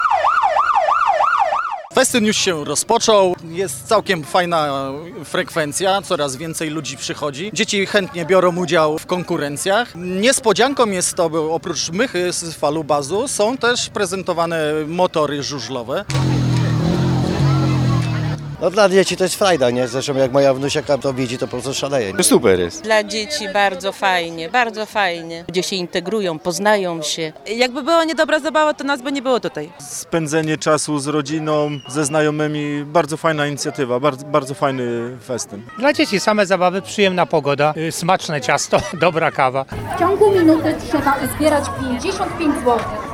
Trwa festyn rodzinny w zielonogórskiej Łężycy